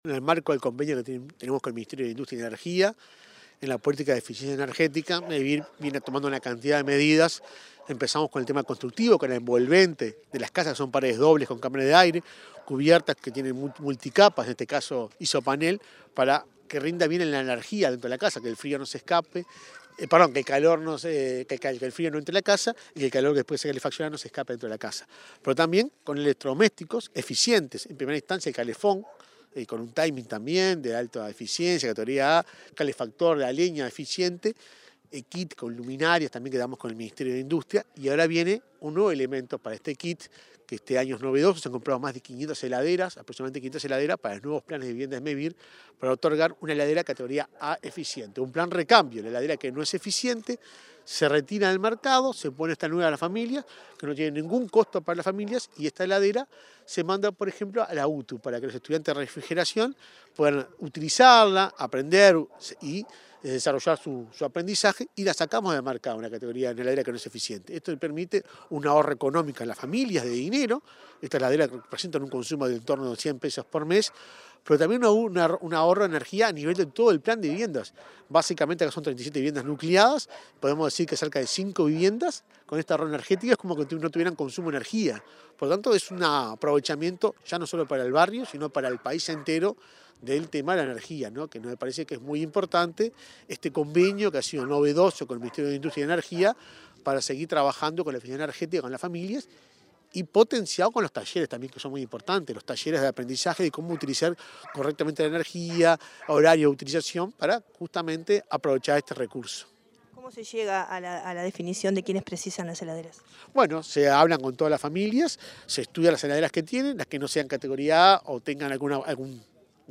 Declaraciones del presidente de Mevir, Juan Pablo Delgado
Declaraciones del presidente de Mevir, Juan Pablo Delgado 28/08/2023 Compartir Facebook X Copiar enlace WhatsApp LinkedIn El presidente de Mevir, Juan Pablo Delgado, dialogó con la prensa en Canelones, antes de participar en el acto de entrega de equipamiento eficiente a beneficiarios del plan de viviendas de la localidad de Santa Rosa.